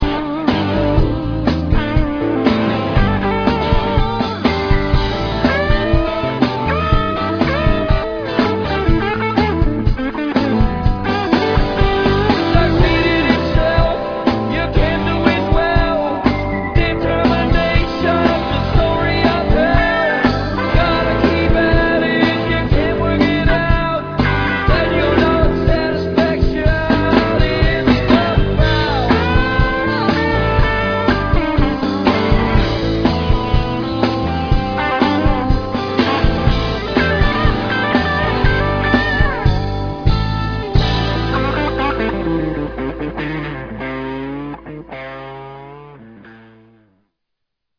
Intro-Am-G x 2